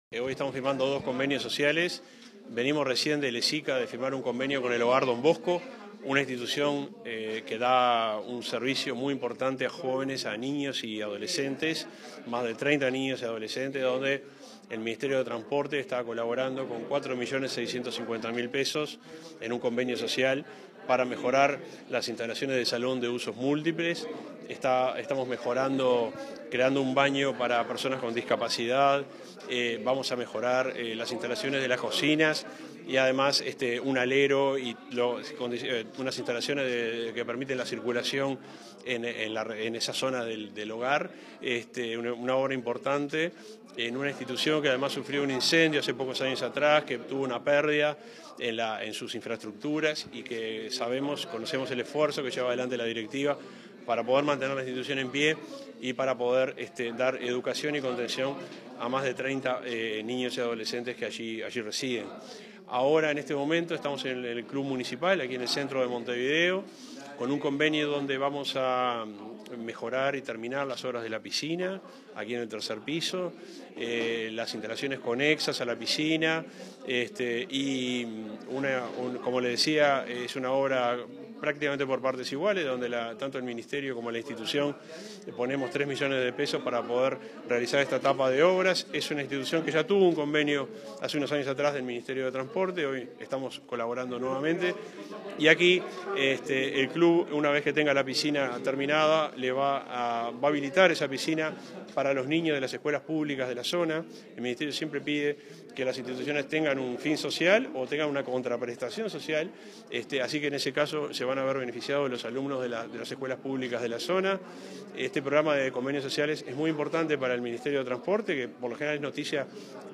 Entrevista al ministro interino del MTOP, Juan José Olaizola